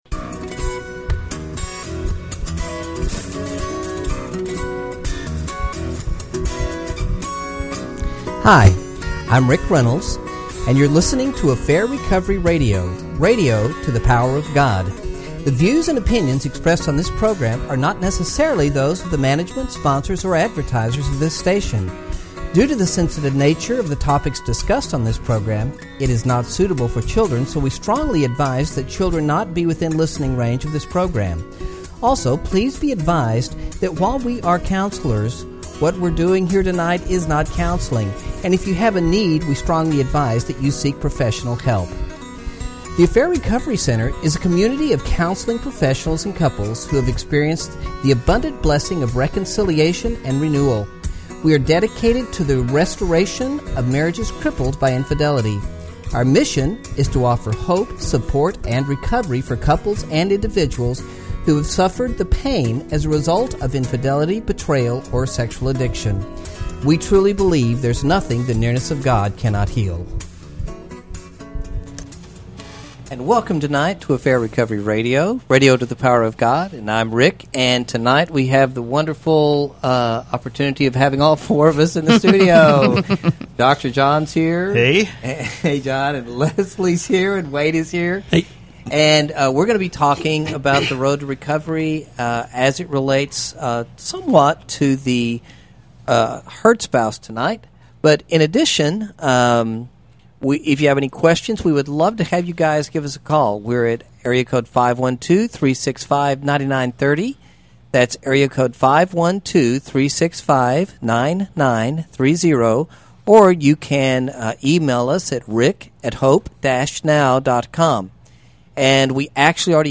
The whole team, joined by callers, discusses what it's like on the road to recovery.